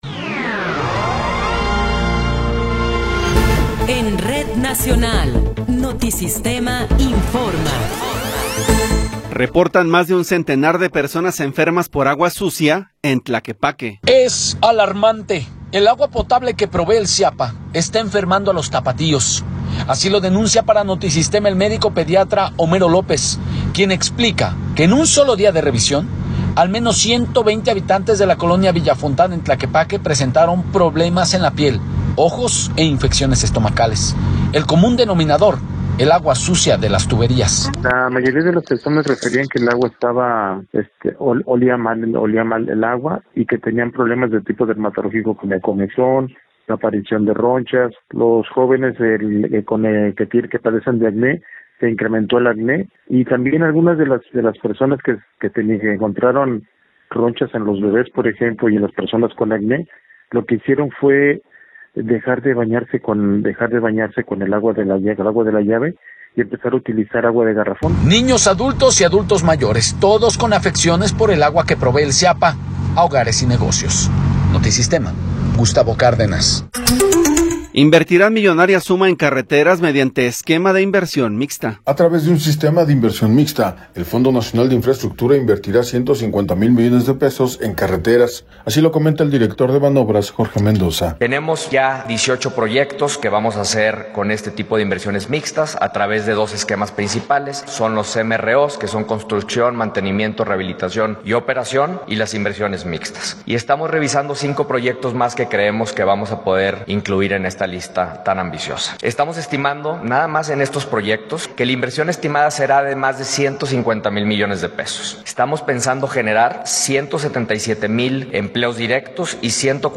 Noticiero 11 hrs. – 4 de Marzo de 2026
Resumen informativo Notisistema, la mejor y más completa información cada hora en la hora.